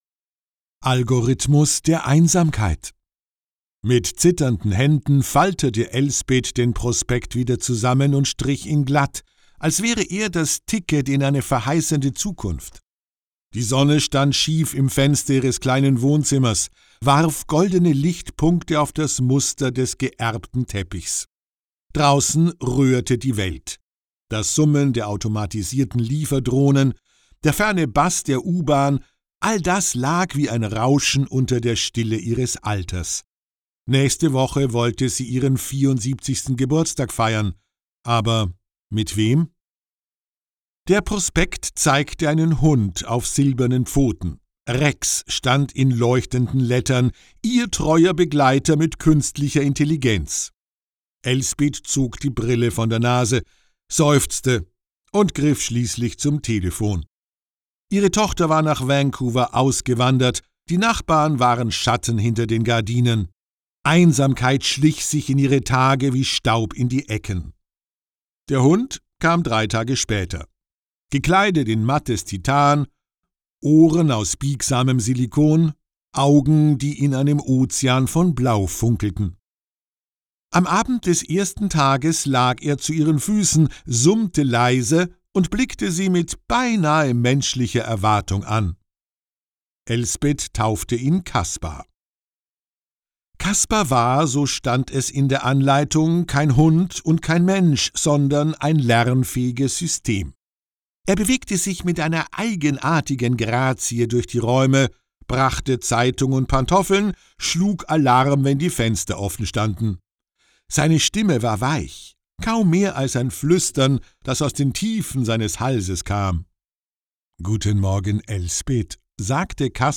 Keine-Gute-Nacht-Geschichten-Hoerprobe.mp3